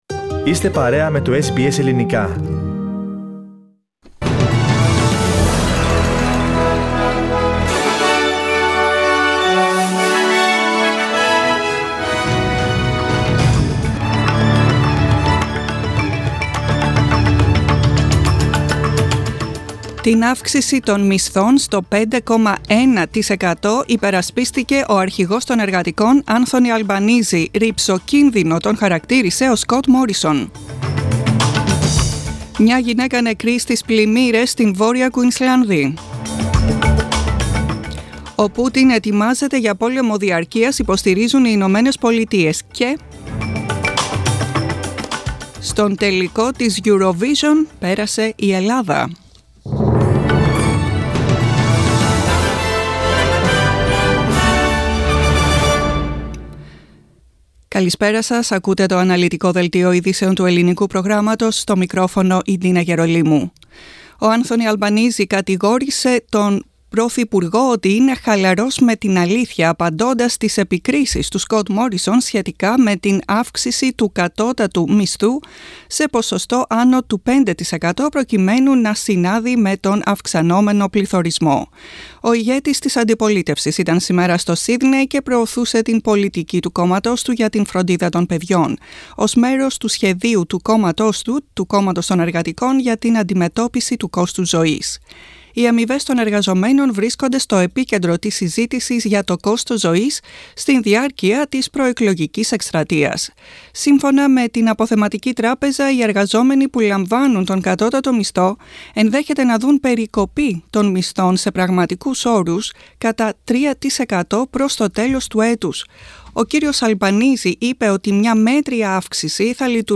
Δελτίο ειδήσεων 11 Μαίου 2022